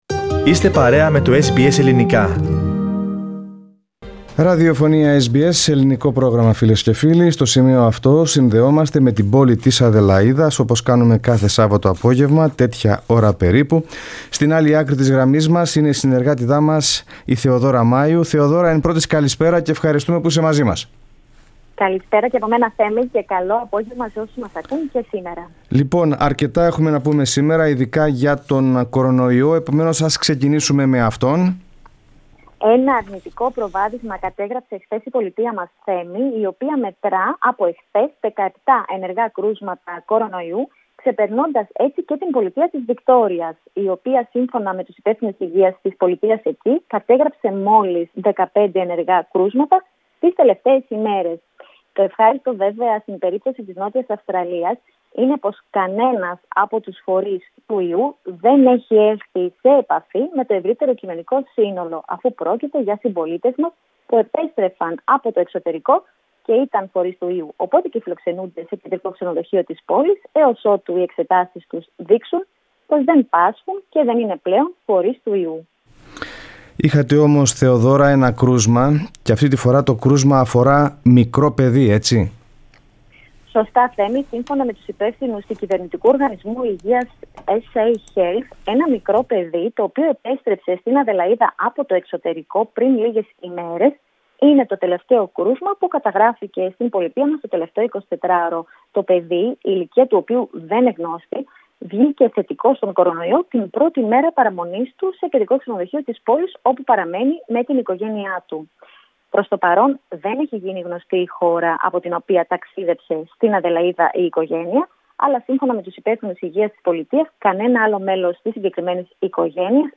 The situation with the coronavirus, the information provided by South Australia- Health and some of the latest activities of the Greek Orthodox Community of South Australia, constitute the content of this week’s report from Adelaide.